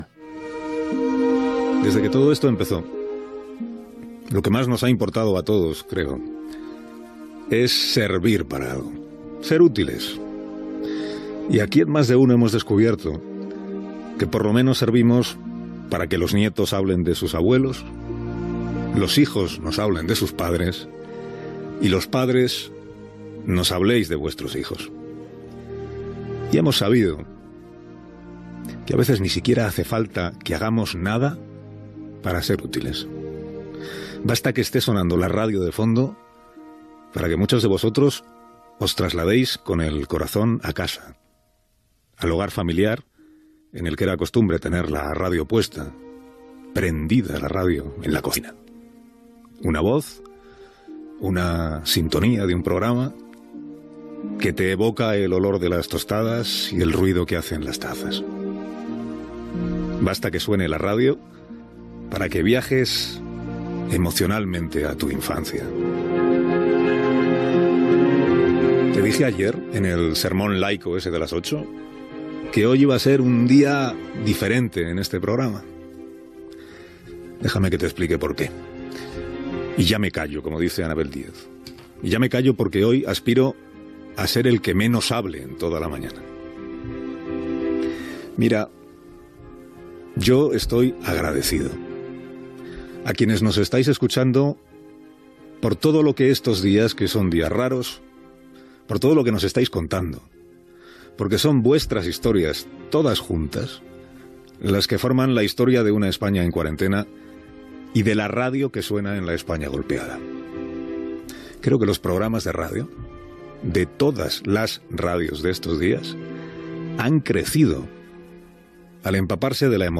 Portada de Carlos Alsina.
Info-entreteniment